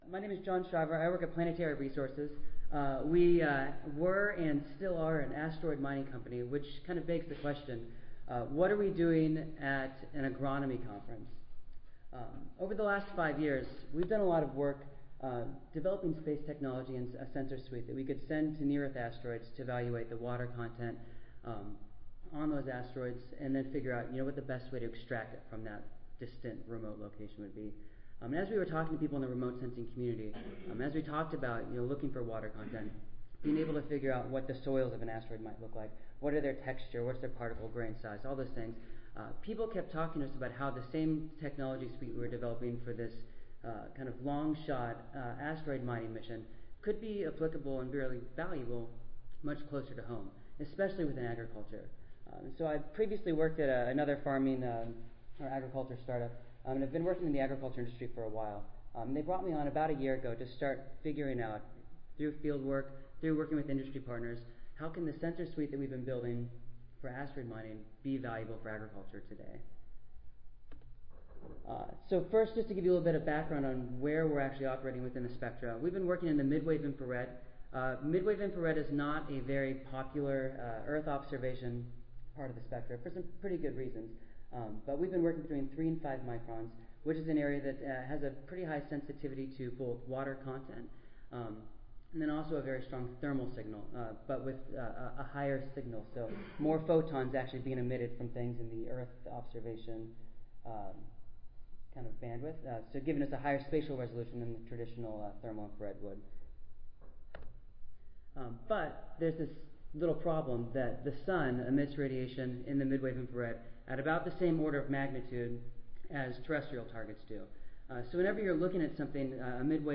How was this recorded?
Session: Symposium--Soil Moisture Sensing for Crop Health Assessment and Management (ASA, CSSA and SSSA International Annual Meetings (2016))